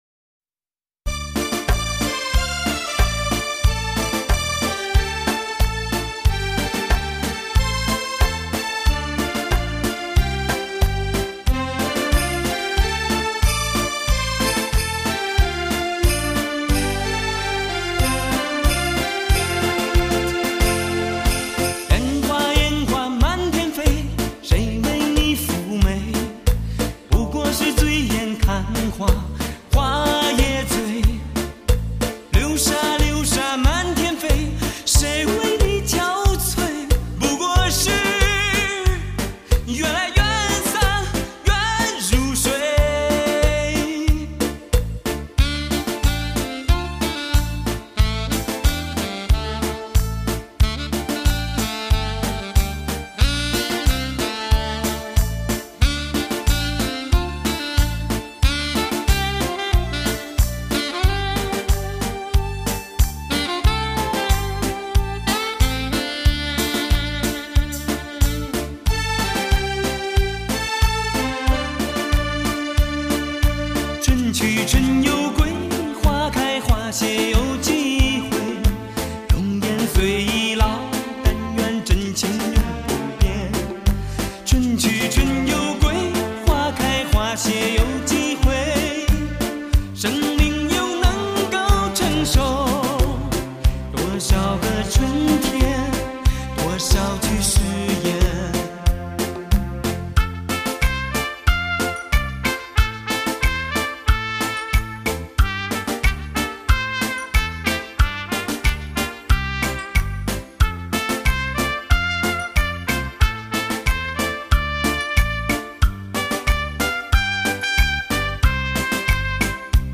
吉特帕